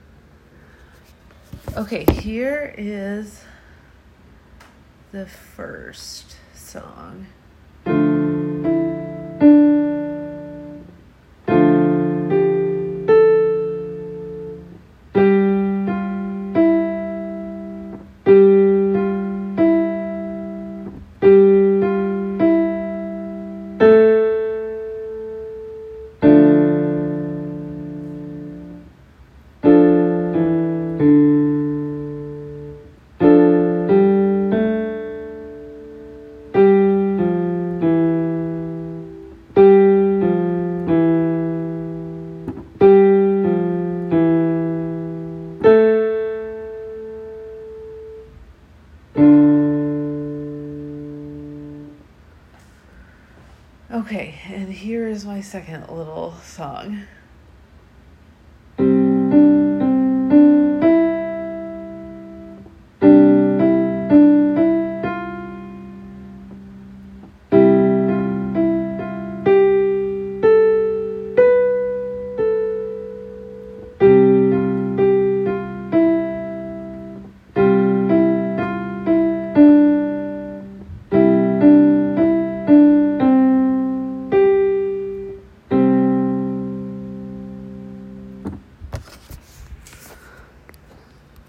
It’s just two short little songs.
What I like is that I’m learning enough about music that I can picture these written as music and I know what keys they’re both in (the first is in D minor, the second in C major).
keyboard-songs-sept-13.m4a